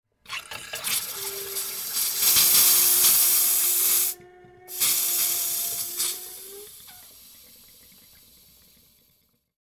Air Sputtering In Water Pipes – Faucet Coughing Sound Effect
Nature Sounds / Sound Effects / Water Sounds
Air-sputtering-in-water-pipes-faucet-coughing-sound-effect.mp3